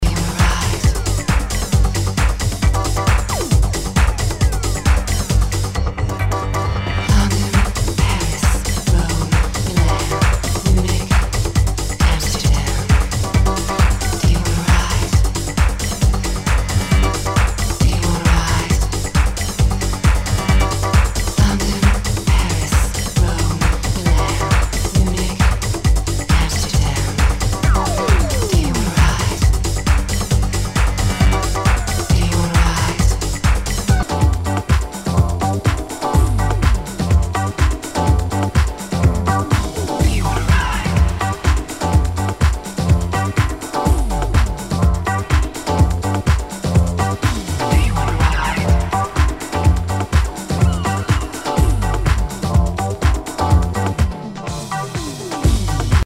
HOUSE/TECHNO/ELECTRO
ナイス！プログレッシブ・ハウス！